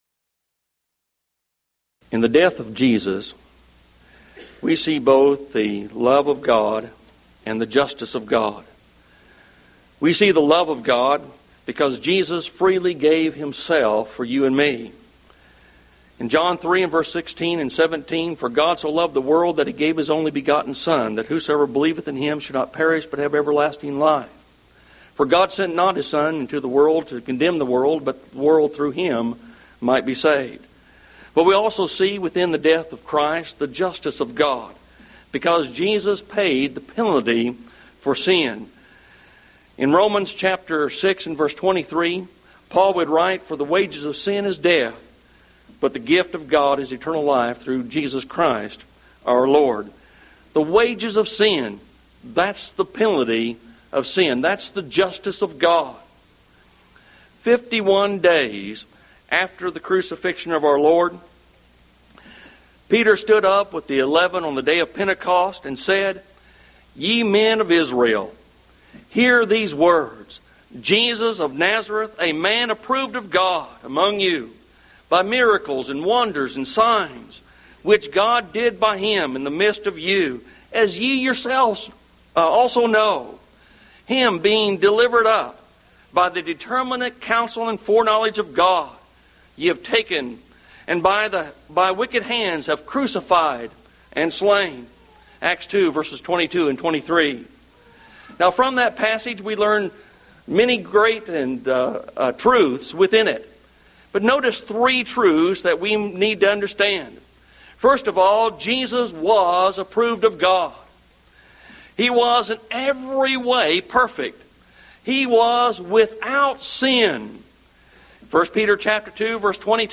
Event: 1998 Mid-West Lectures